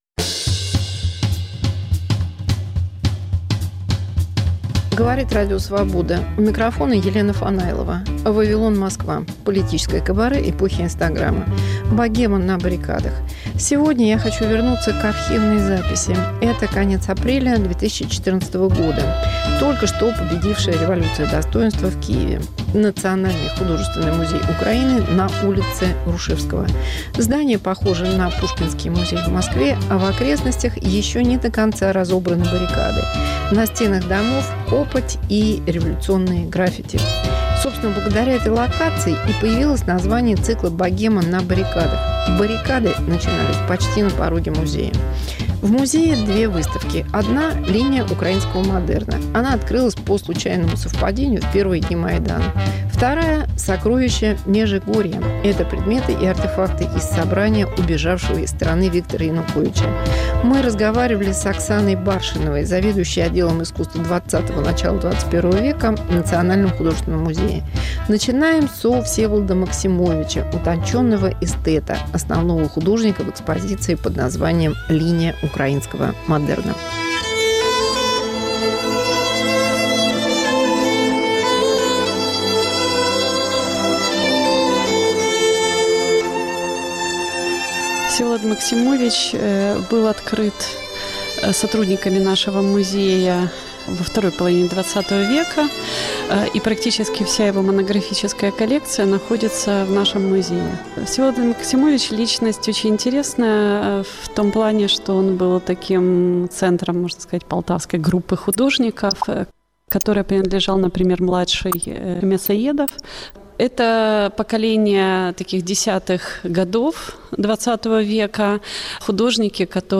Киевское интервью апреля 2014 года.